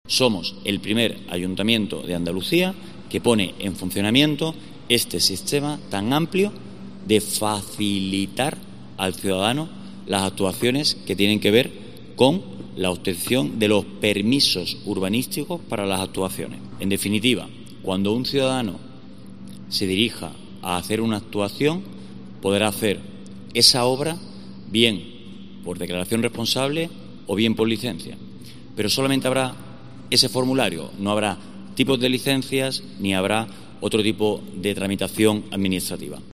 Declaraciones del concejal de Urbanismo de Málaga, Raúl López.